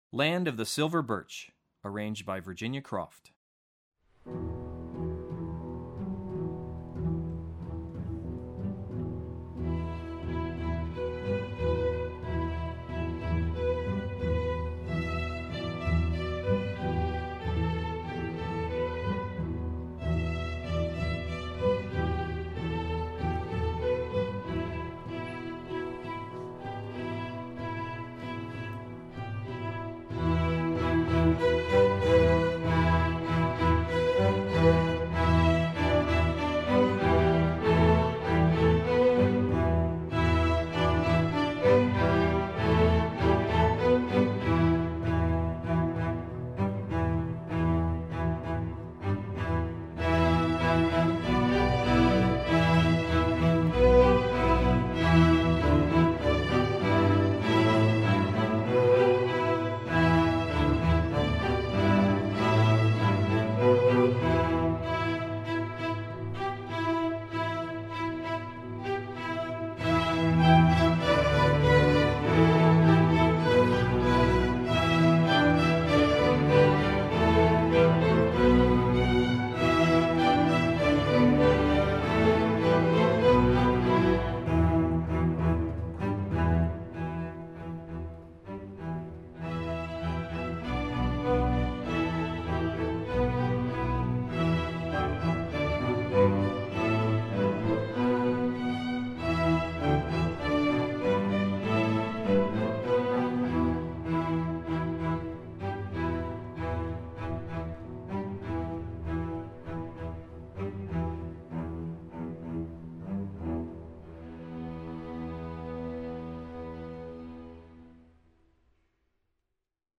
Composer: Canadian Folk Song
Voicing: String Orchestra